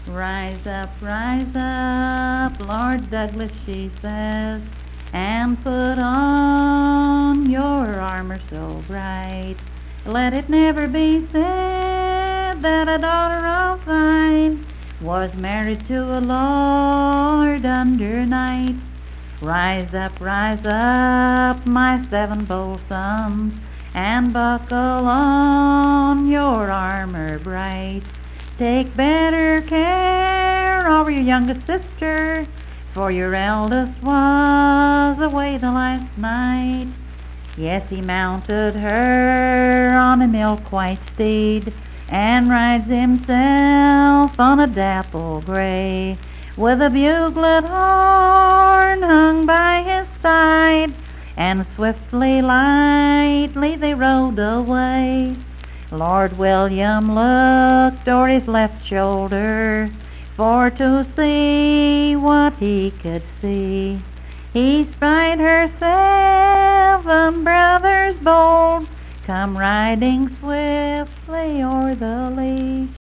Unaccompanied vocal music
Verse-refrain 4(4).
Performed in Coalfax, Marion County, WV.
Voice (sung)